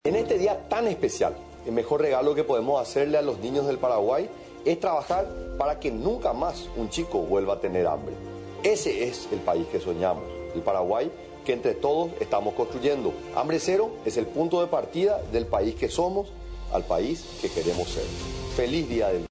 A través de un audio viralizado en las redes sociales, el presidente de la República Santiago Peña, expresó sus felicitaciones a todos los niños y niñas en conmemoración al Día en que se recuerda la batalla de Acosta Ñu.
100-pdte-santiago-pena-mensaje-dia-del-nino.mp3